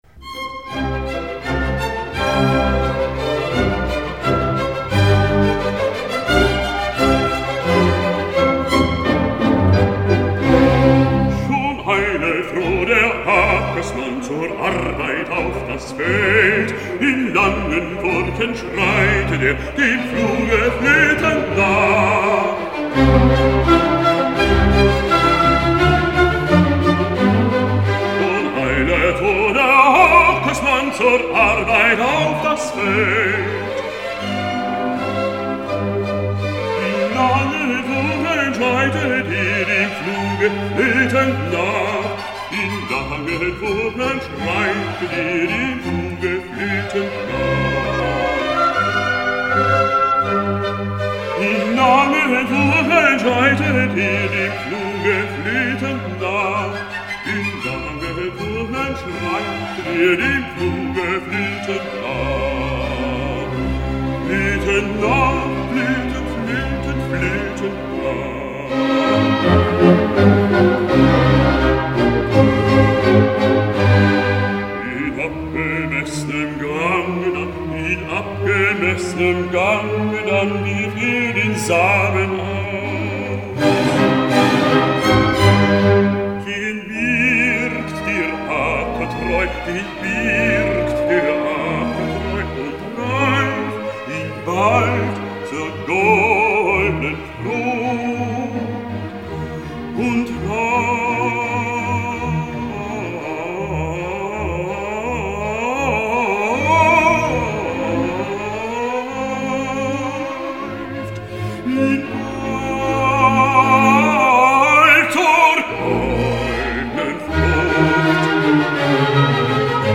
Joseph Haydn: Arie des Simon aus "Die Jahreszeiten"